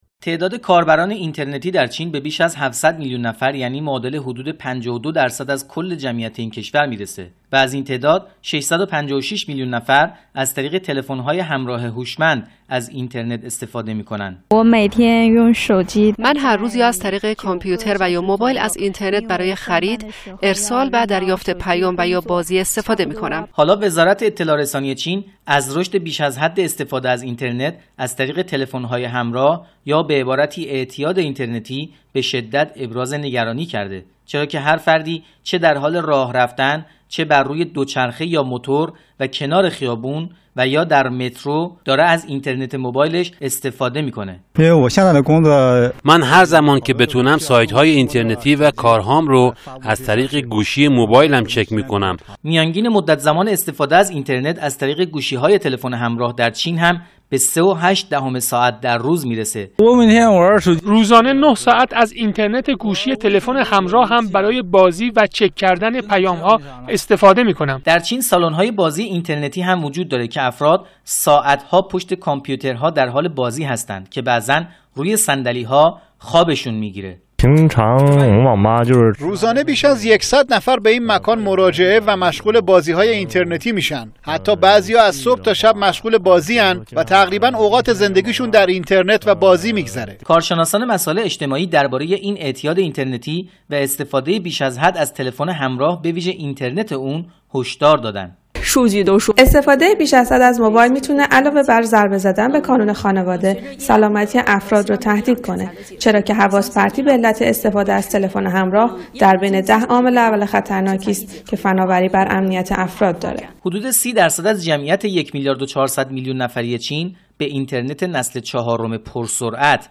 Гузориши ҳамкорамон